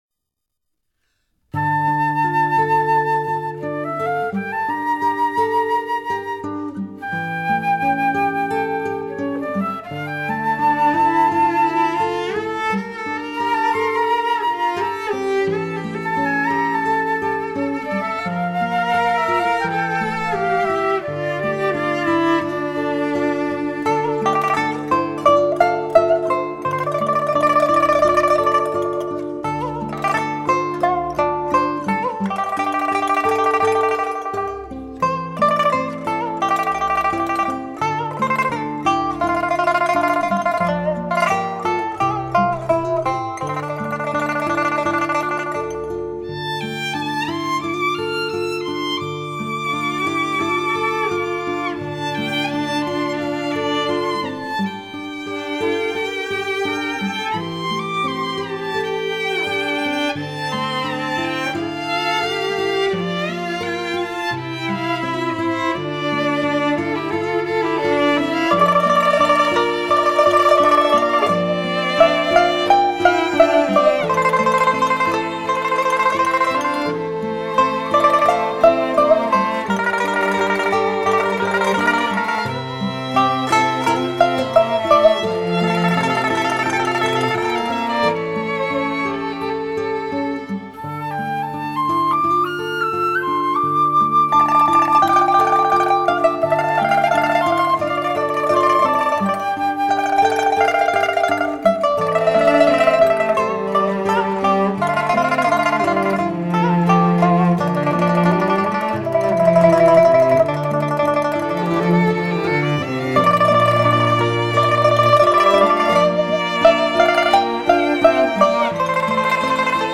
绝美雅乐
其富有变化的演奏风格，
深情的投入，
都使这张专辑在众多琵琶曲中独树一帜，
不含人间烟火的风格突显，